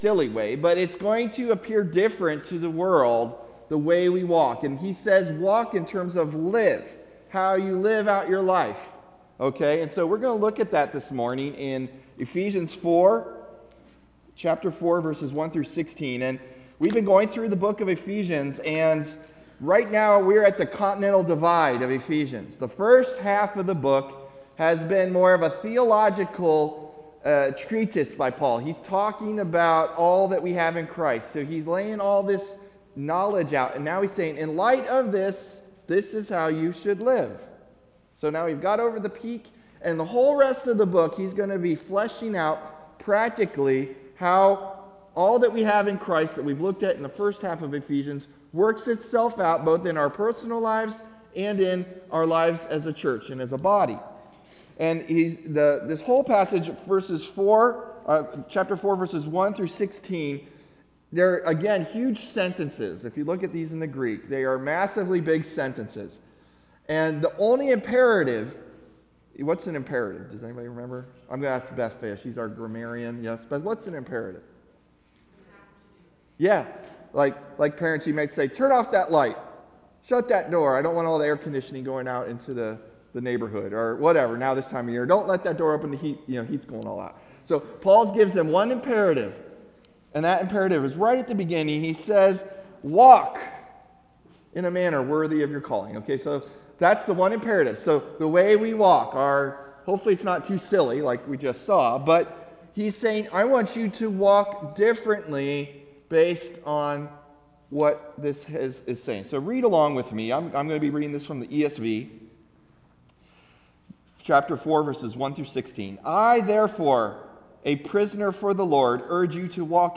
November 5 Sermon | A People For God